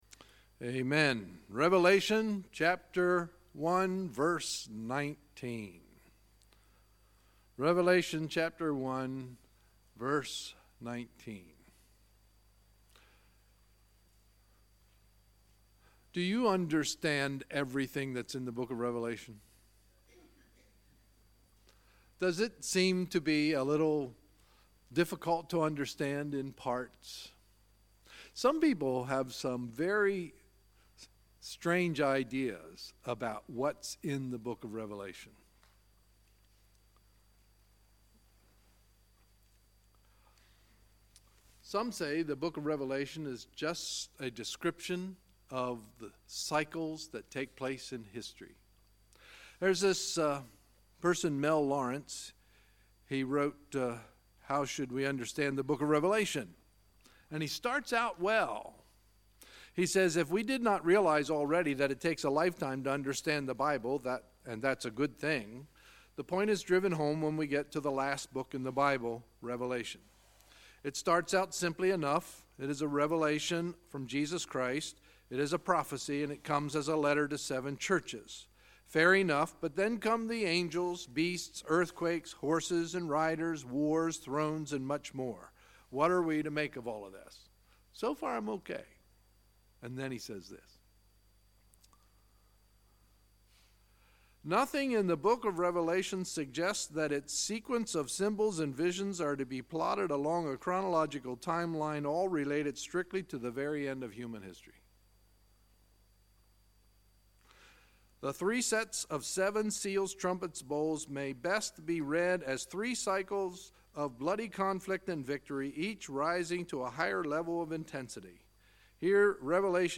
Sunday, February 11, 2018 – Sunday Evening Service